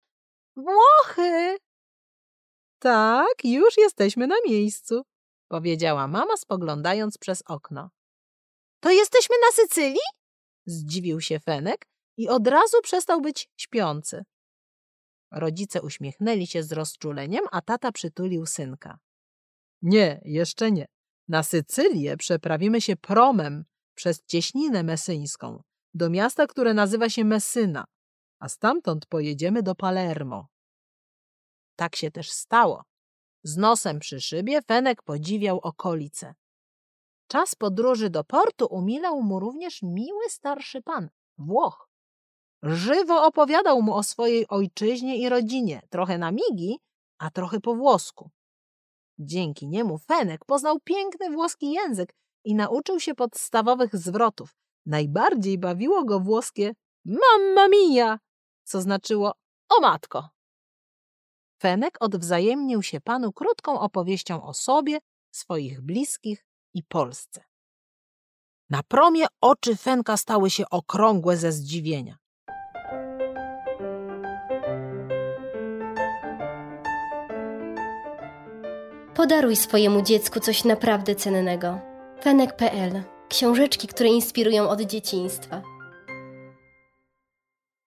Włoskie przygody Fenka - Małgorzata Górna - audiobook